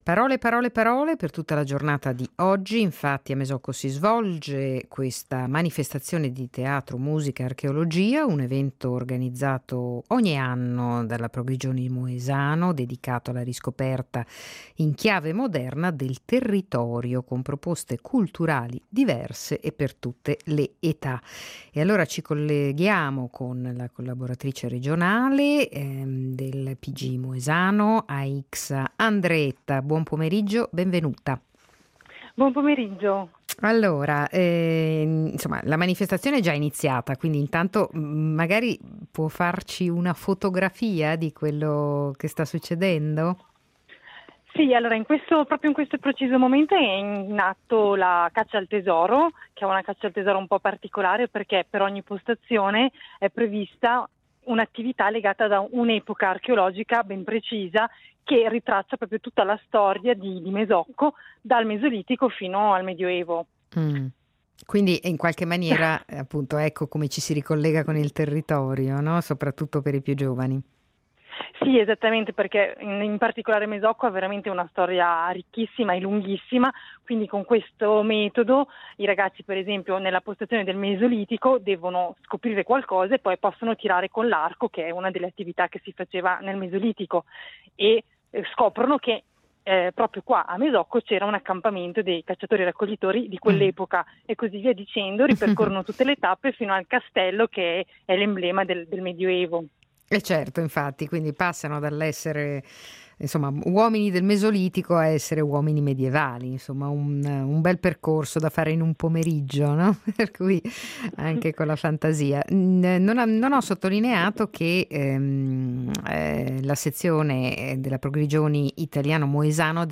in diretta da Mesocco per introdurre “Parole parole parole - teatro musica archeologia”, un evento organizzato dalla Pgi Moesano, associato quest’anno ai festeggiamenti per i 100 anni della Pro Grigioni italiano.